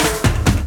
02_06_drumbreak.wav